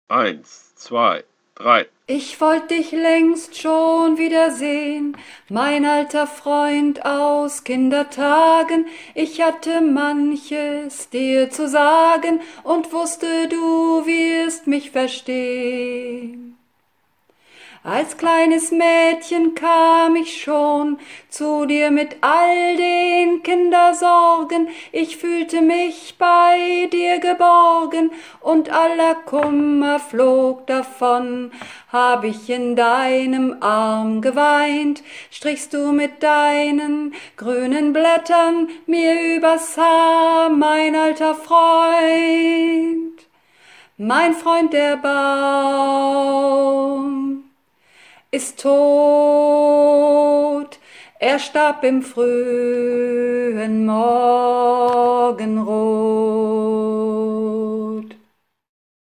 Übungsaufnahmen - Mein Freund, der Baum
Runterladen (Mit rechter Maustaste anklicken, Menübefehl auswählen)   Mein Freund, der Baum (Sopran-Alt 1)
Mein_Freund_der_Baum__Sopran-Alt1.mp3